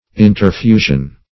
Interfusion \In`ter*fu"sion\, n. [L. interfusio.]